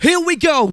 VOX SHORTS-2 0007.wav